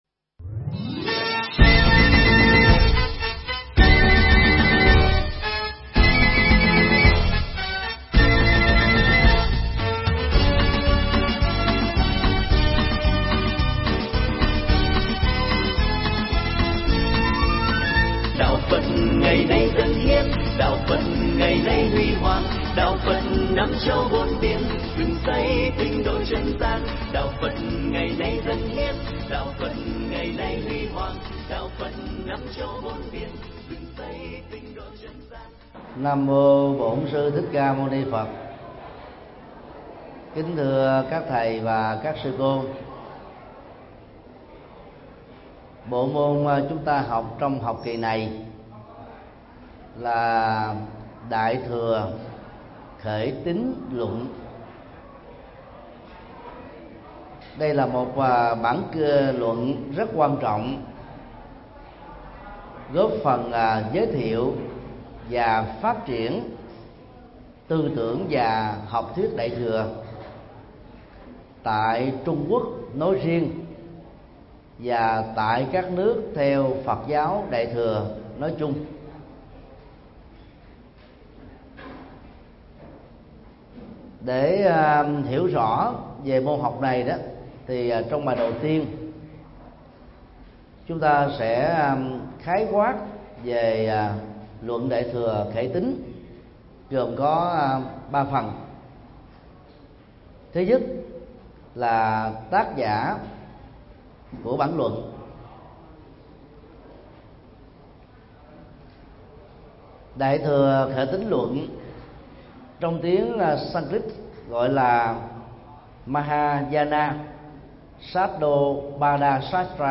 Mp3 Pháp Thoại Khởi Tín Luận 01: Khái Quát Về Khởi Tín Luận - TT. Thích Nhật Từ Giảng tại học viên Phật giáo Việt Nam tại TP. HCM, ngày 22 tháng 12 năm 2014